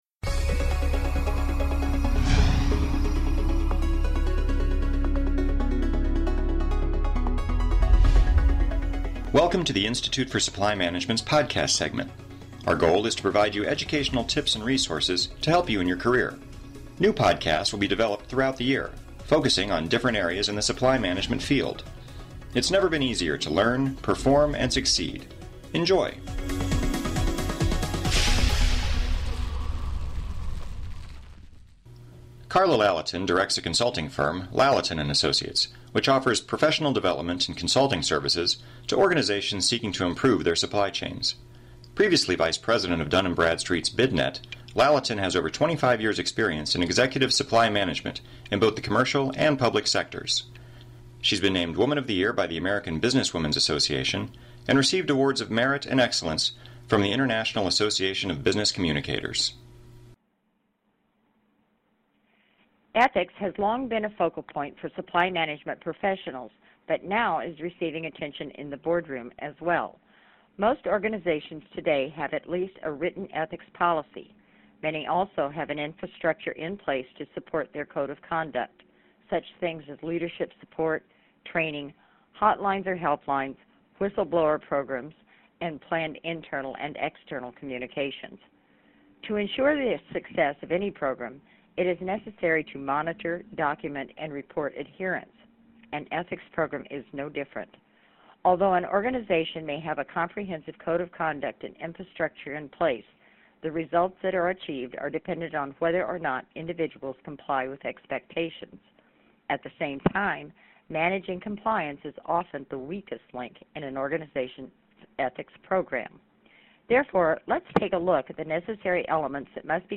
Keeping Tabs on Ethics Script Length: 13 minutes Type: Solo Please click below to take a brief survey on this podcast.